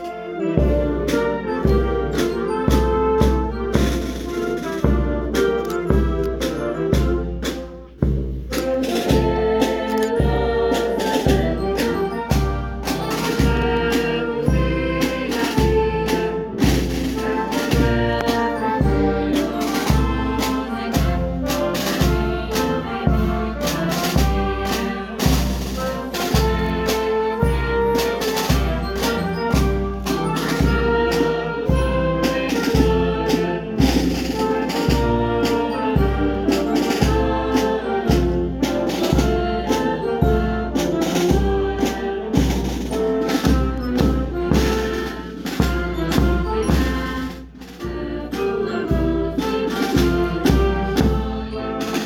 As celebrações continuaram em frente à sede da banda, onde os músicos entoaram o hino da academia.